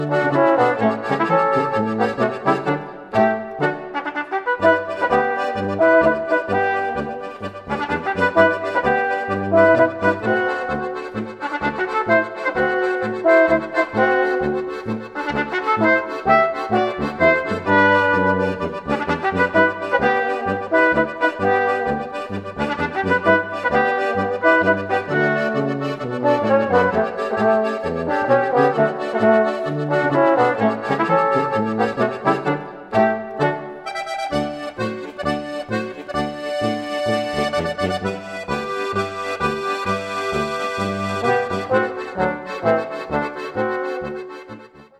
Besetzung: Volksmusik/Volkstümlich Weisenbläser
Akkordeon
Trompete
Tuba
Basstrompete